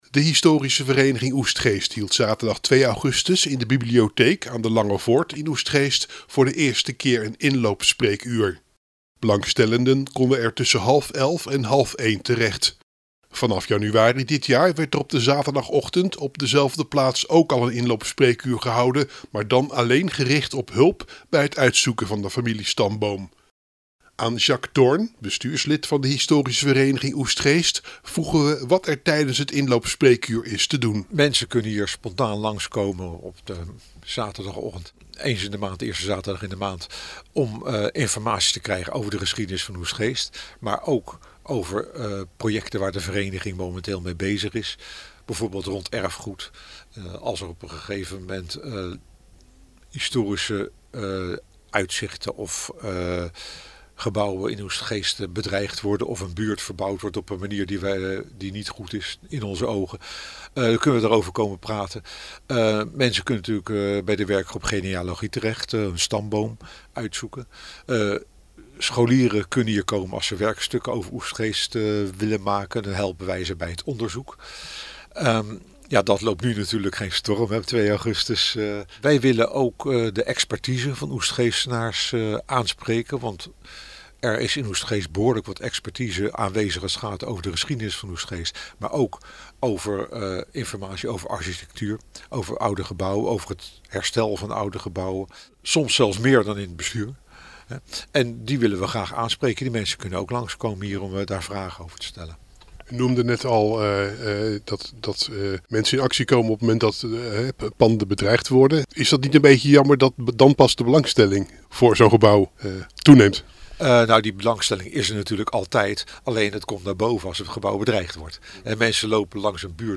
Verslaggever
in gesprek met